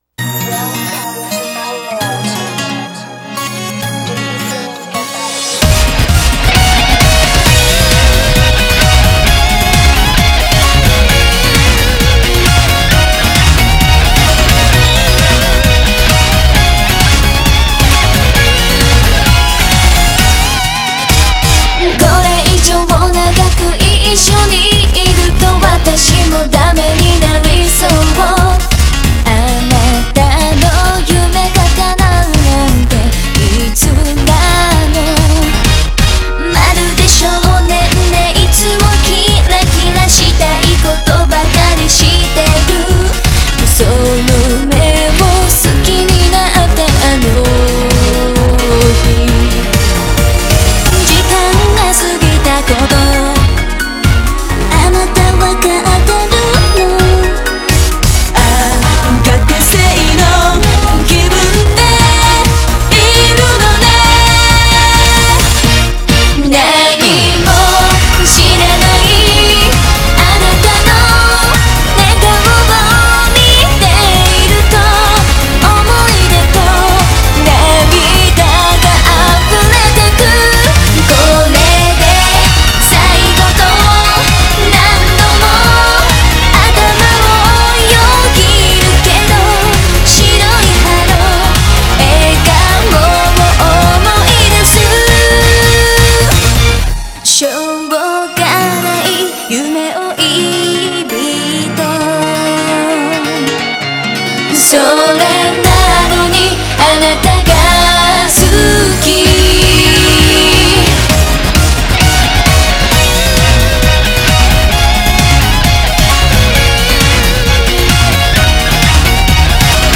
BPM132